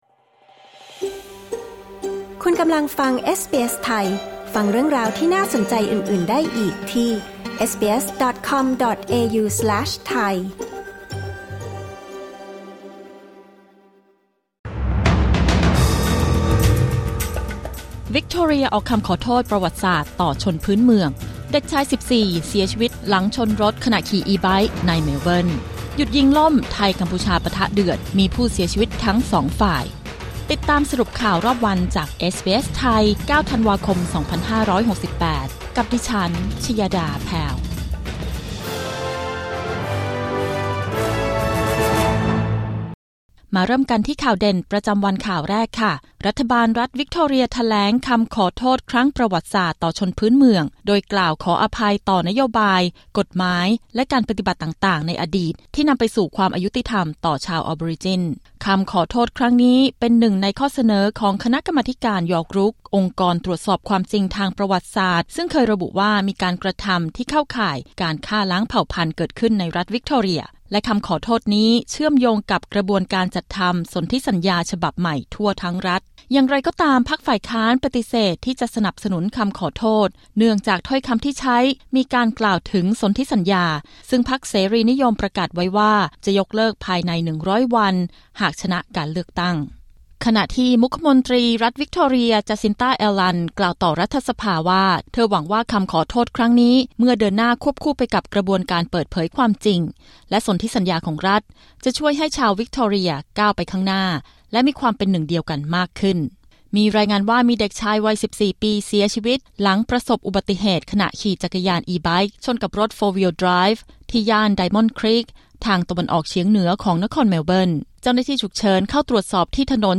สรุปข่าวรอบวัน 9 ธันวาคม 2568